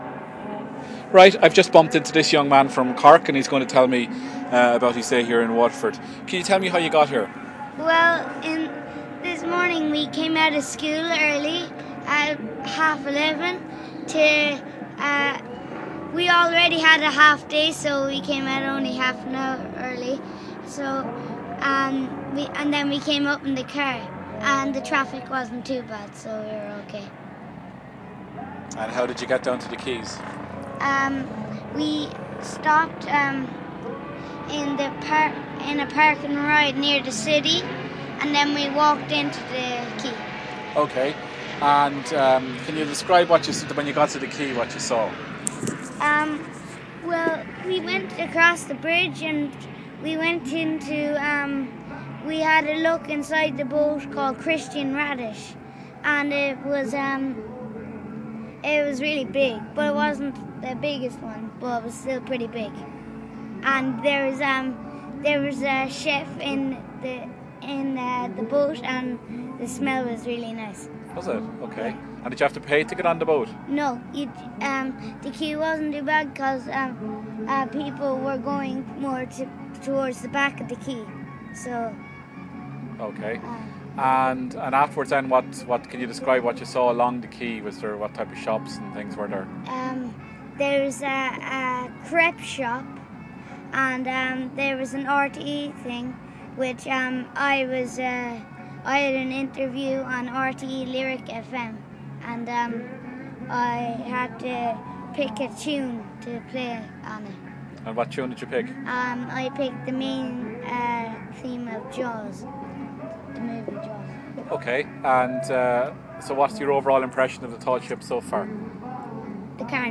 Cork lad talks about the Tall Ships